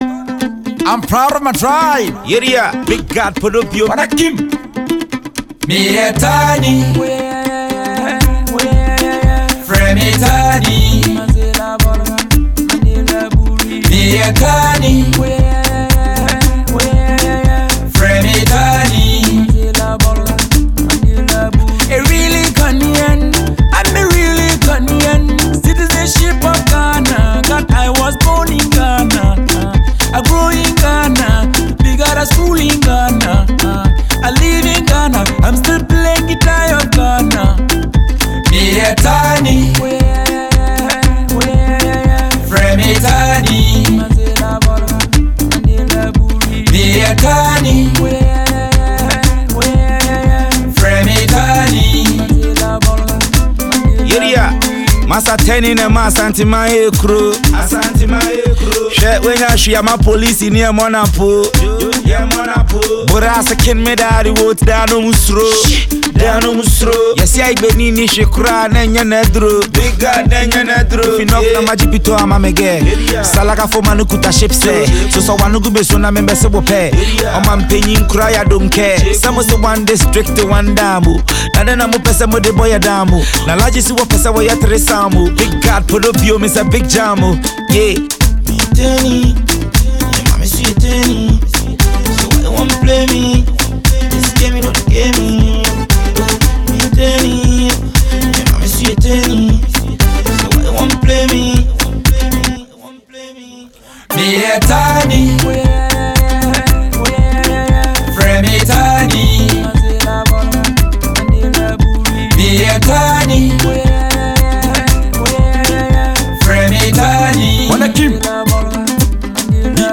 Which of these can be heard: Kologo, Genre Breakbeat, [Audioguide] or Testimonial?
Kologo